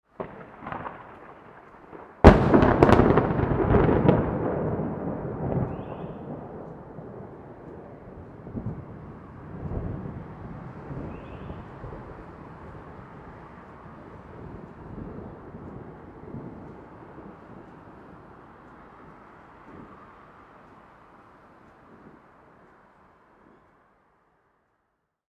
Loud Thunder Strike Sound Effect
Description: Loud thunder strike sound effect. A lightning strike with thunder. Authentic close-up recording. Nature sounds.
Loud-thunder-strike-sound-effect.mp3